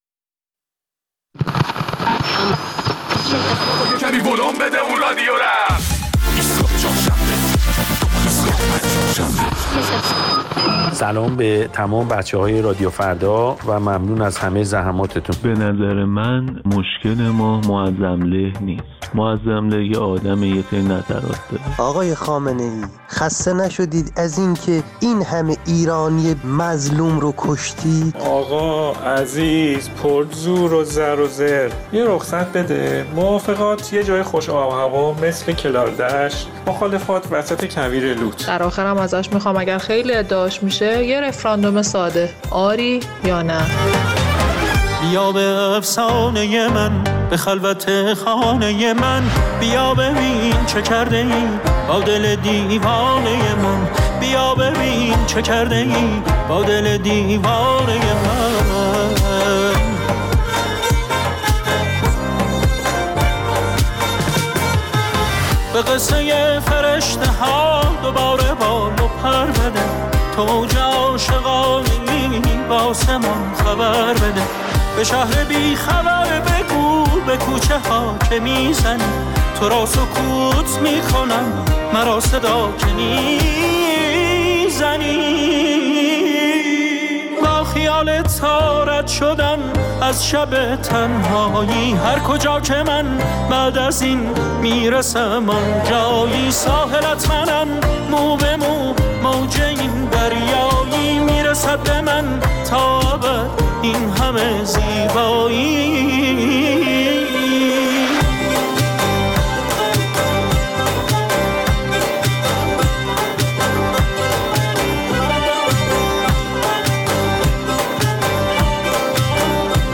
در این برنامه از شنودگان ایستگاه فردا خواسته‌ایم حرف‌هایشان را خطاب به رهبر جمهوری اسلامی برایمان بگویند